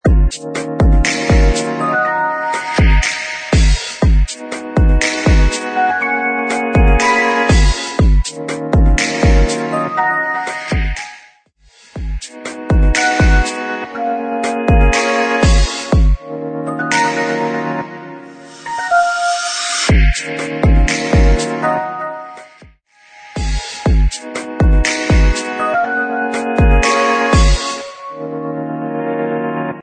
Smooth Electronic